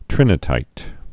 (trĭnĭ-tīt)